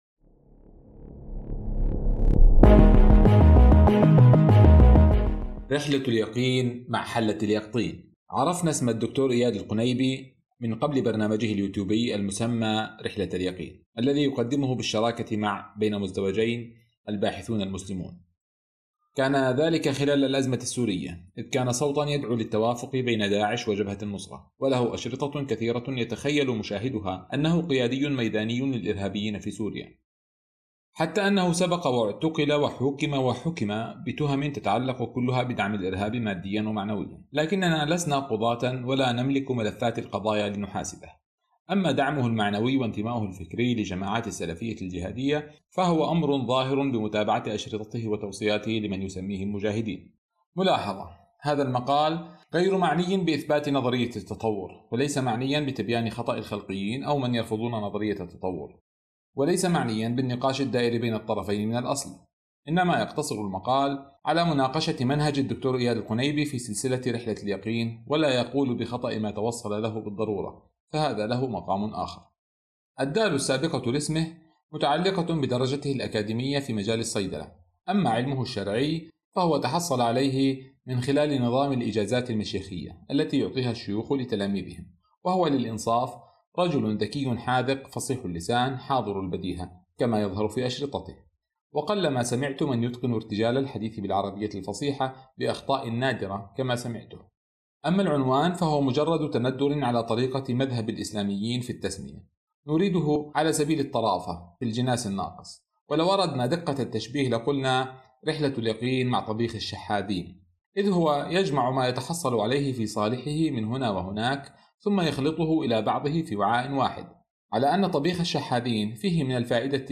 قراءة لمقال من المدونة اسمه رحلة اليقين مع حلة اليقطين، يمكنك تشغيلها أسفل الغلاف، أو من البودكاست "كلمة راس".